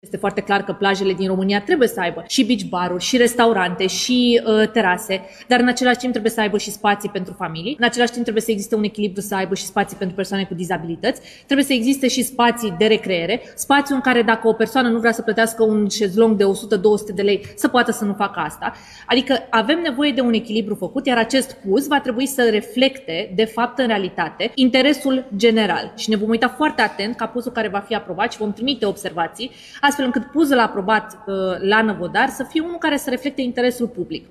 Ministra Mediului, Diana Buzoianu: „Trebuie să existe și spații de recreere, spații în care o persoană, dacă nu vrea să plătească un șezlong de 100 de lei, să poată să nu facă asta”